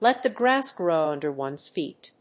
lèt the gráss grów ùnder one's féet
発音